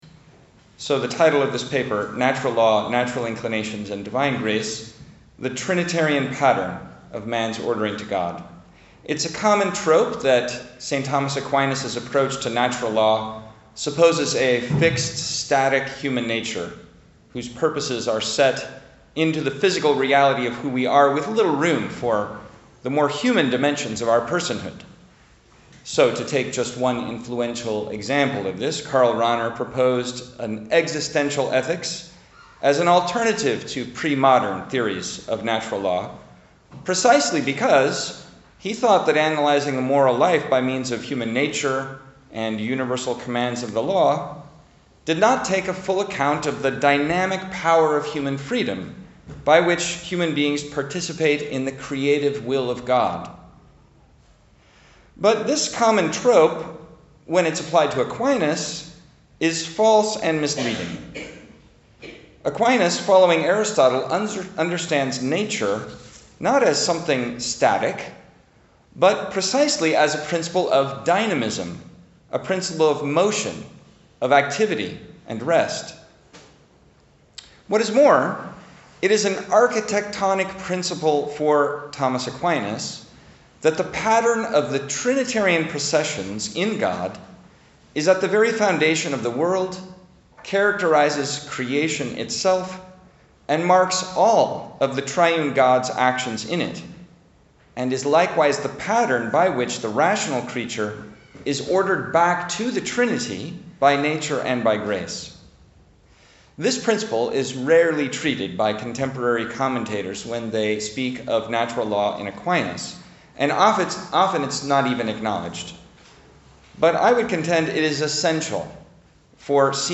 This lecture was given on November 6th, 2023, at Oxford University.